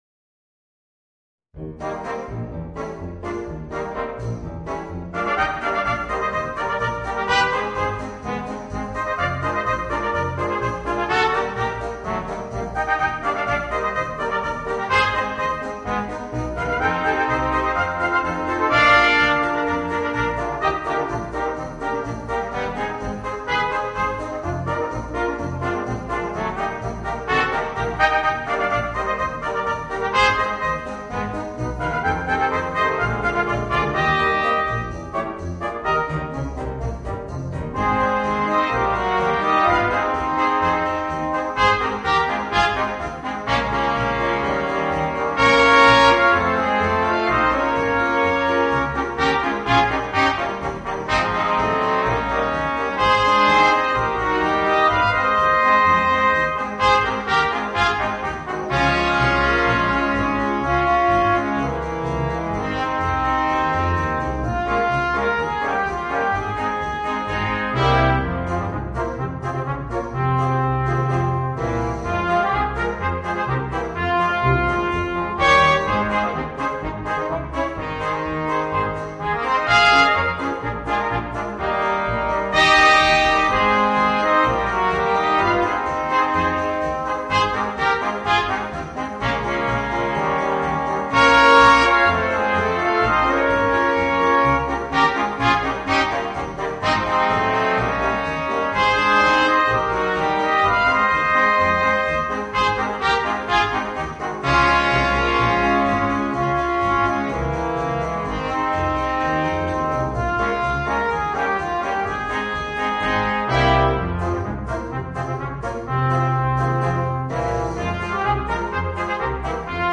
Voicing: 2 Trumpets, Horn, Trombone and Tuba